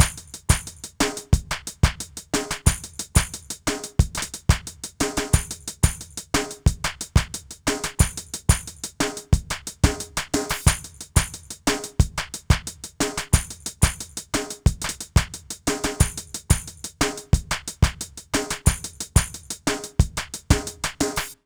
British REGGAE Loop 090BPM.wav